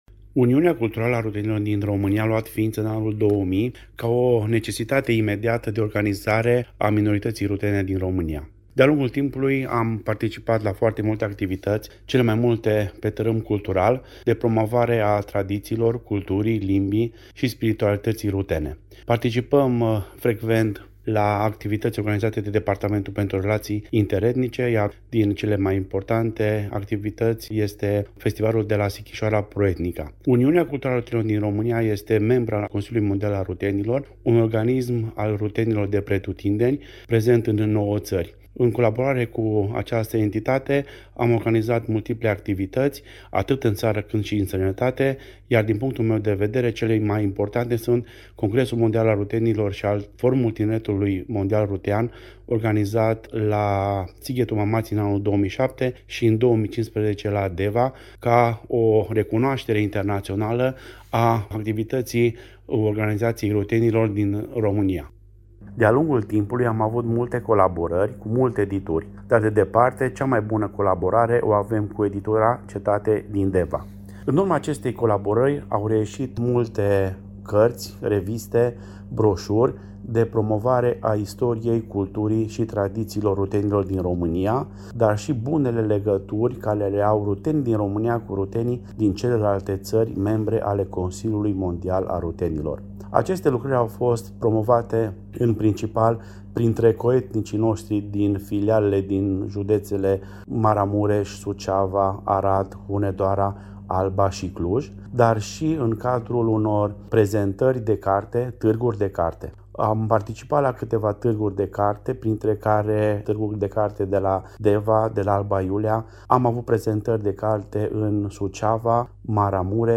(Interviuri Radio Timișoara, în exclusivitate.)